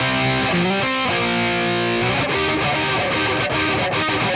AM_RawkGuitar_110-C.wav